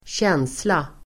Uttal: [²tj'en:sla]